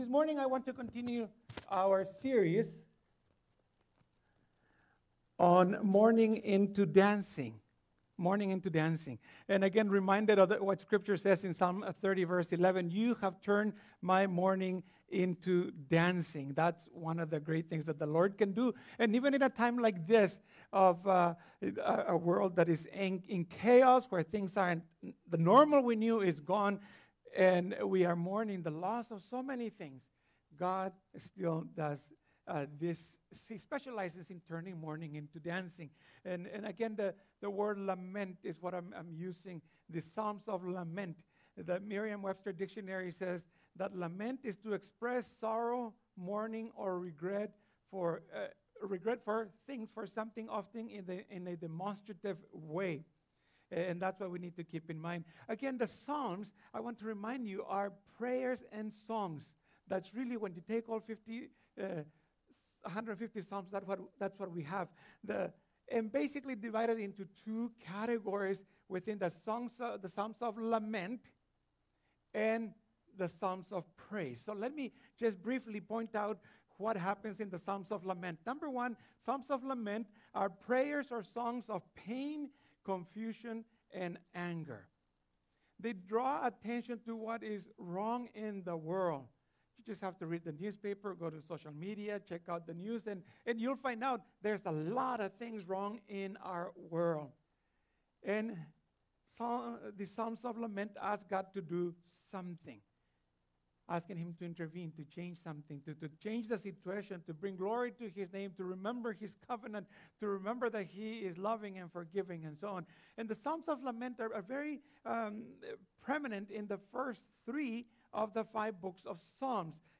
This is the third sermon in our Mourning Into Dancing series and it is based on Psalm 102.